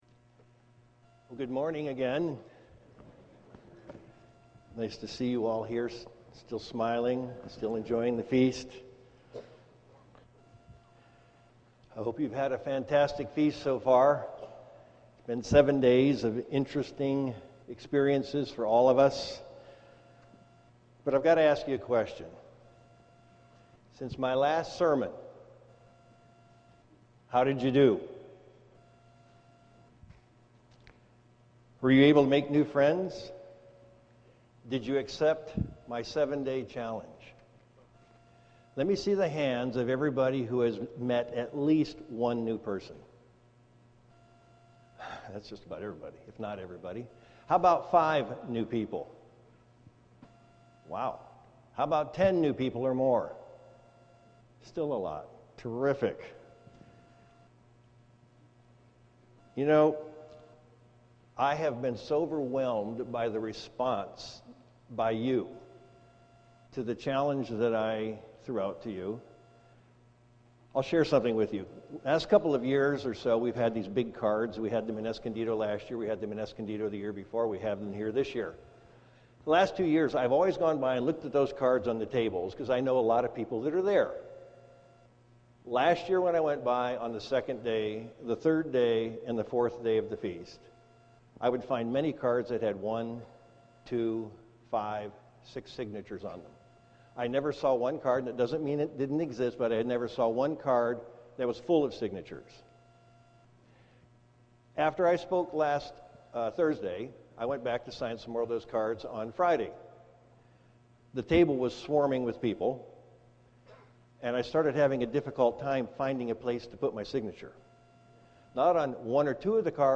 This sermon was given at the Oceanside, California 2013 Feast site.